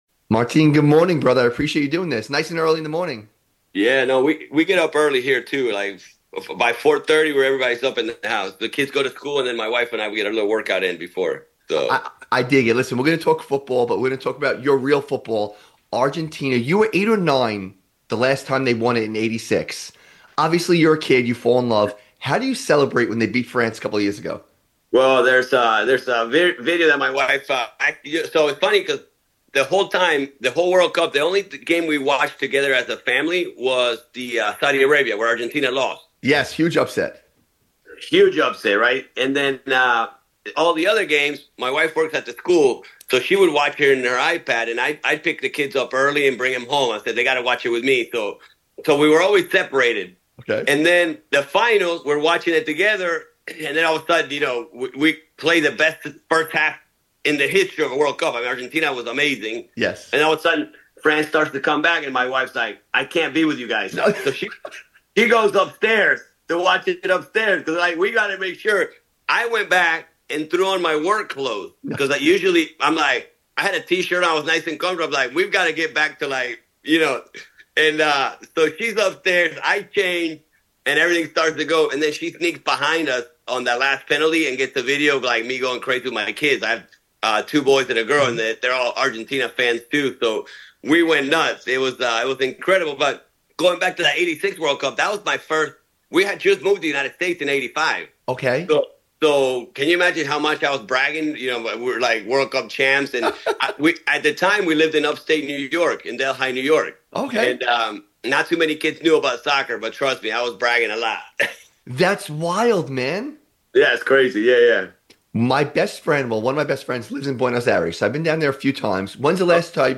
Interview with Martin Gramática